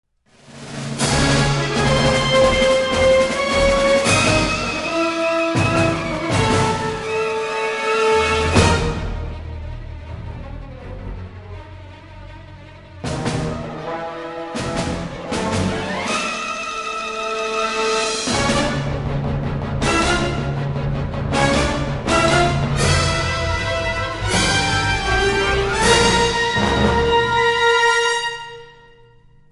Recorded at Todd A-O and Sony Pictures Scoring Stages